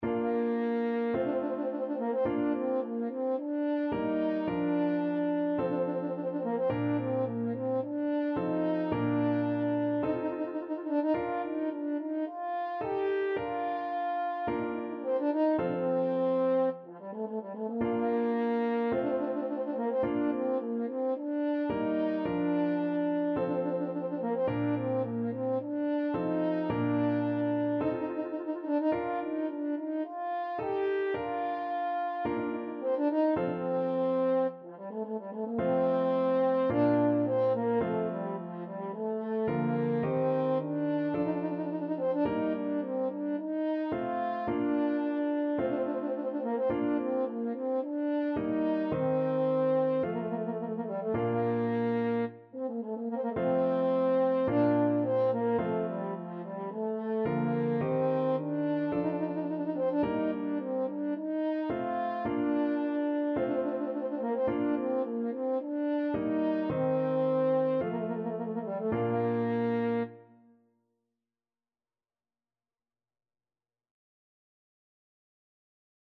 = 54 Slow
4/4 (View more 4/4 Music)
F4-G5
Classical (View more Classical French Horn Music)